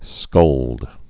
(skŭld)